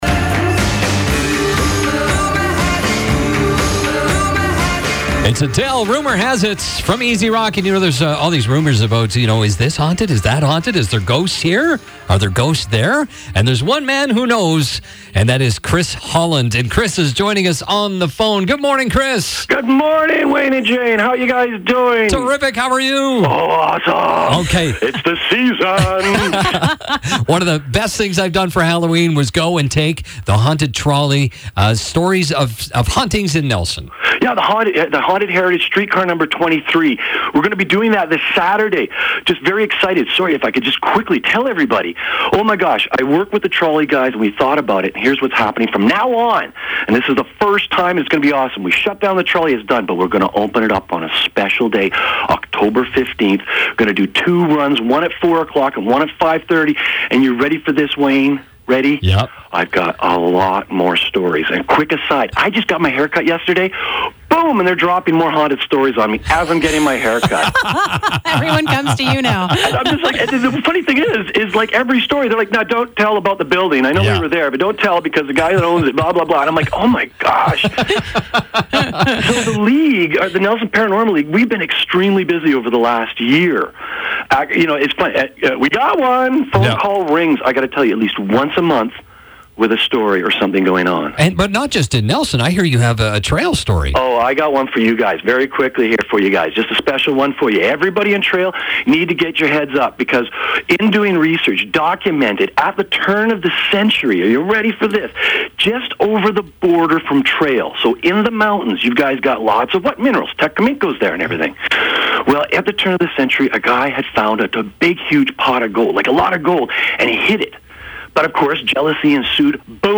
You don't always have to be smooth. You can stumble over your own words sometimes. We forgive all of that for an interview that is delivered with PASSION!